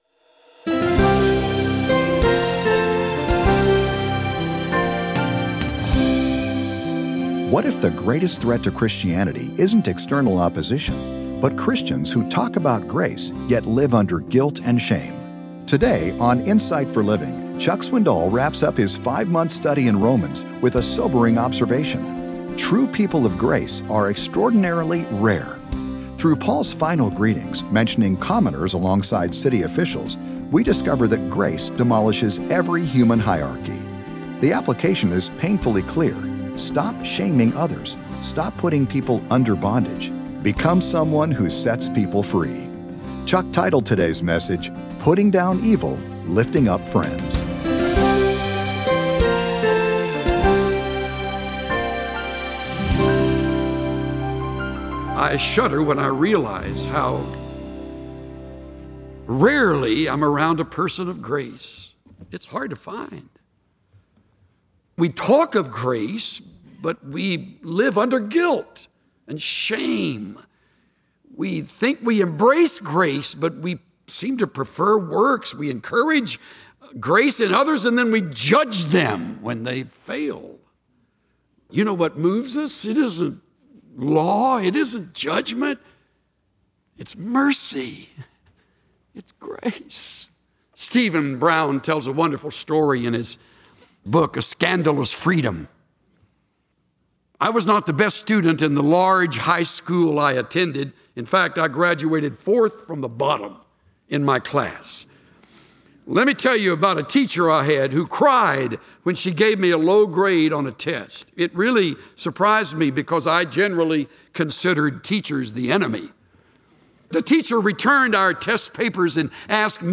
Insight for Living Daily Broadcast